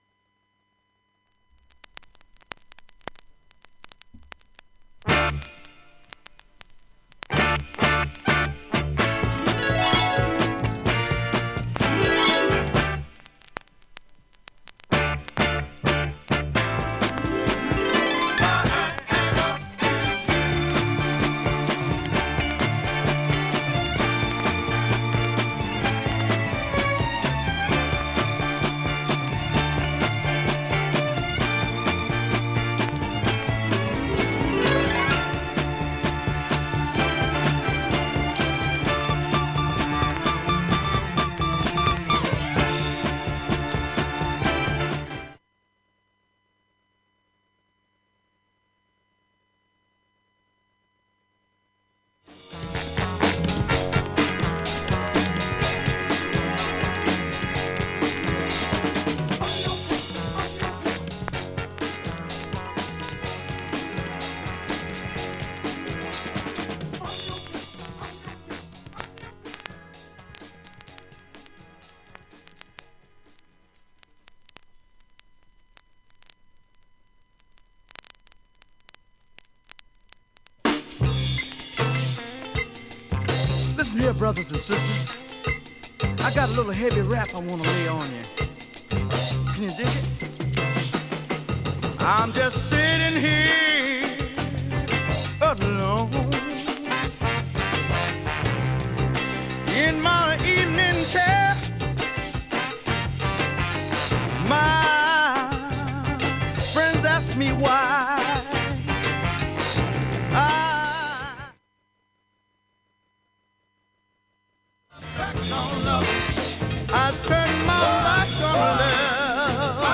ほかの曲間、静かな部などにチリプツ入りますが、